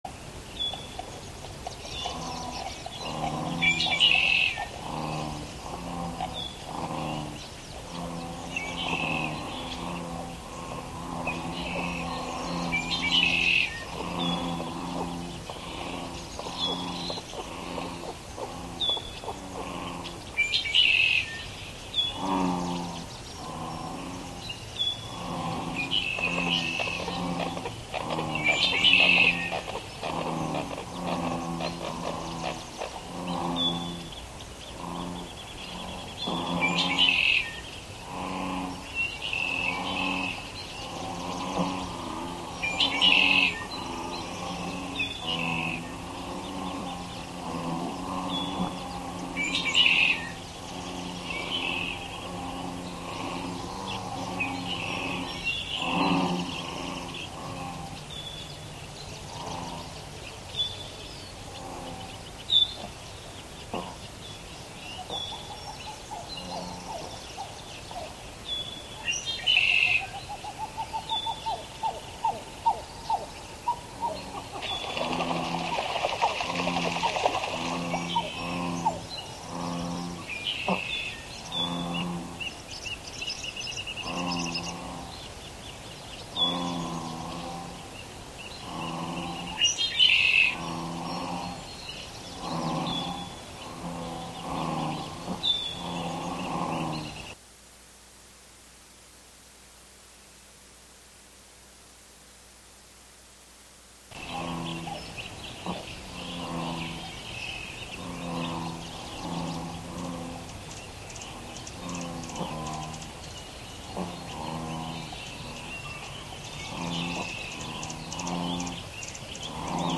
11am Live from Brooklyn, New York
laptop